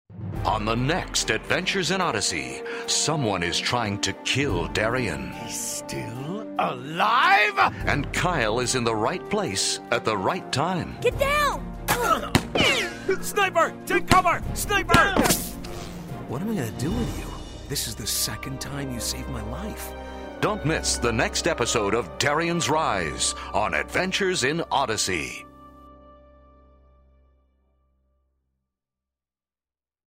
Notes: This audio dramatization is based on Darien's Rise from the Adventures in Odyssey Passages book series.